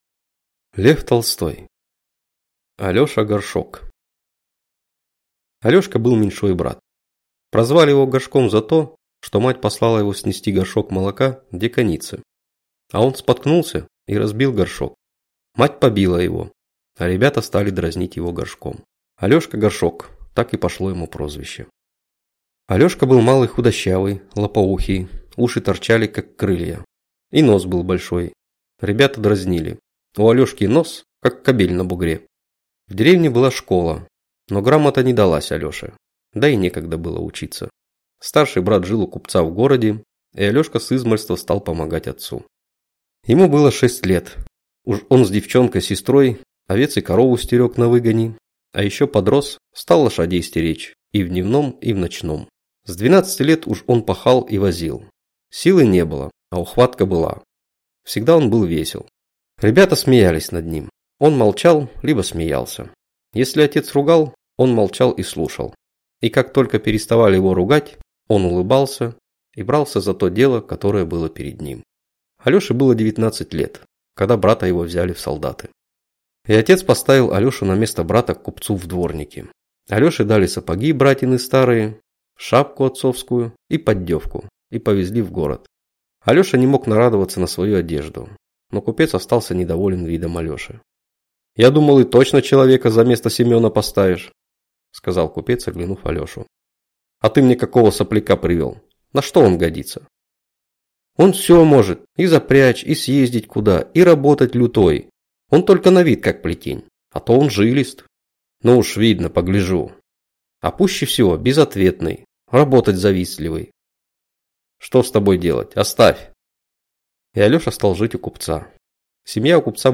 Аудиокнига Алеша Горшок | Библиотека аудиокниг